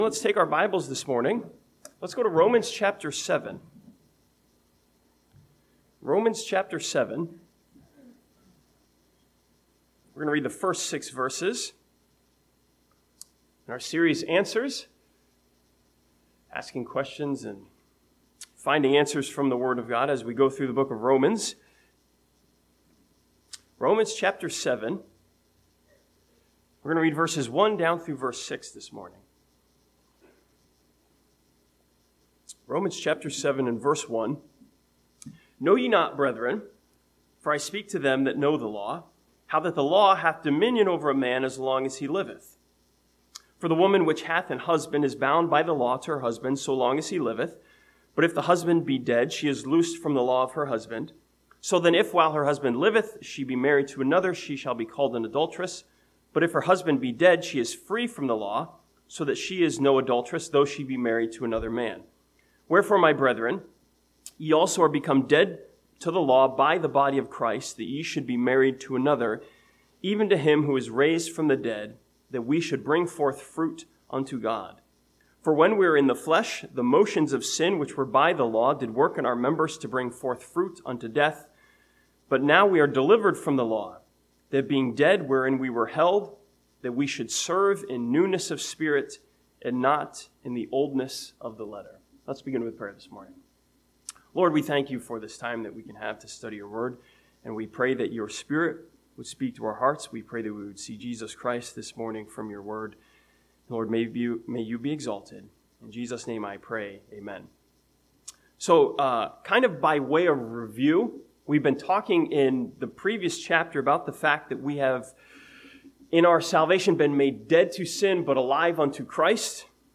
This sermon from Romans chapter 7 challenges believers to not just obey but to live and serve by faith.